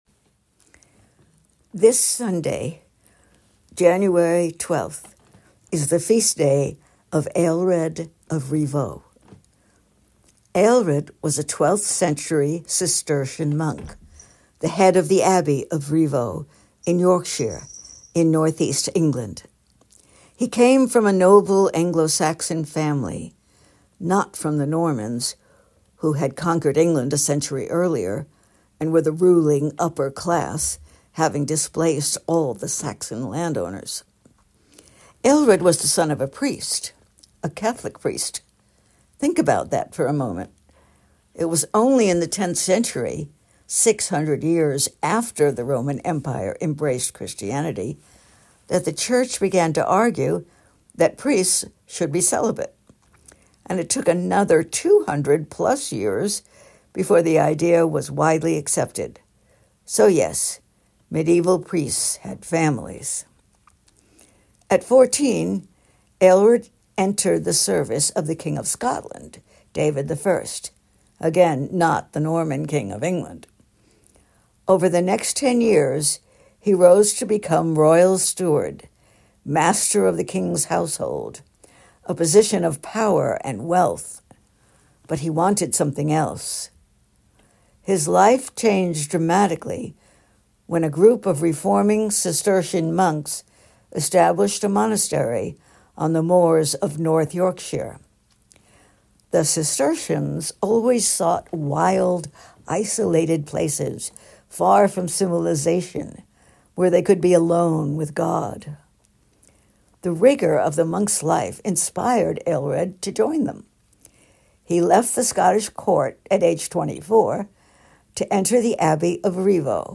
talk on Aelred.